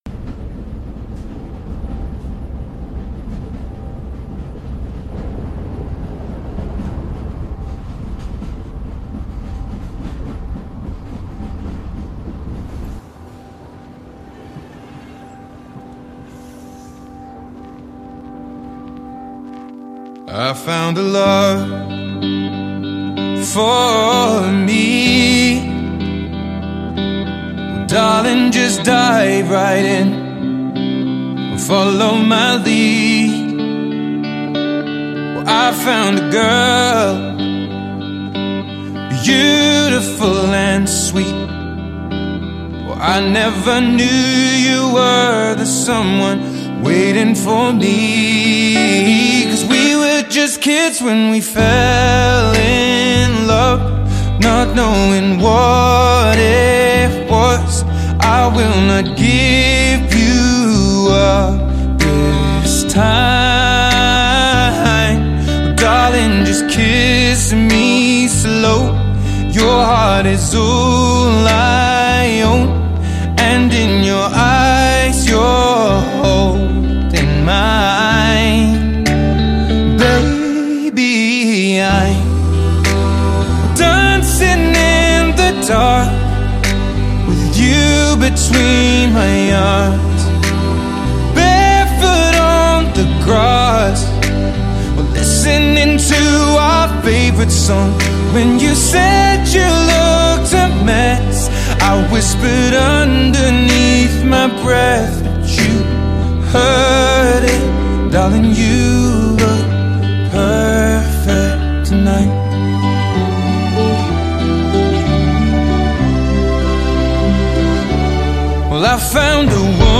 la chanson en Sol